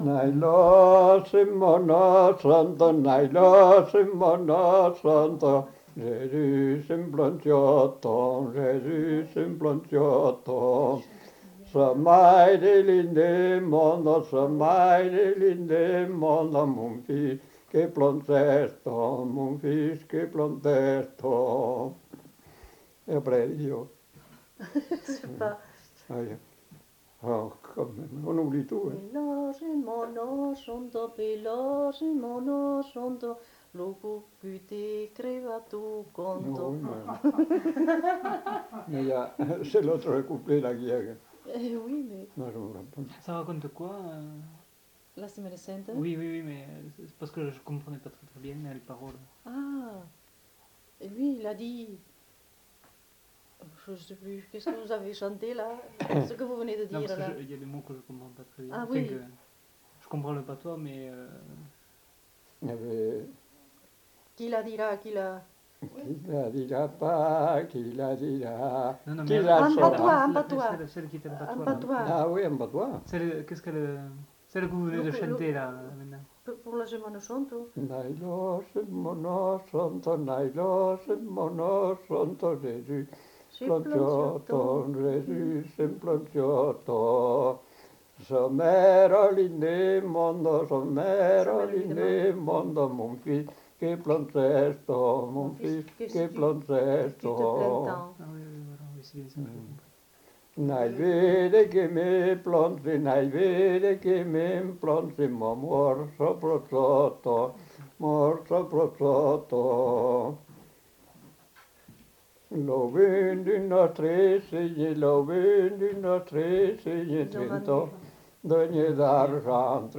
Genre : chant
Effectif : 1
Type de voix : voix d'homme
Production du son : chanté
Classification : quêtes-Rois, Pâques, guillanneus et divers